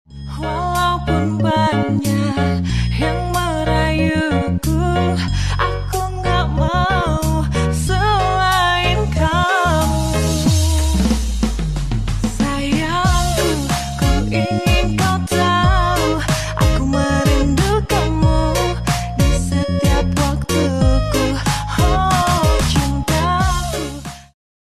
Kruk as matic 250cc By sound effects free download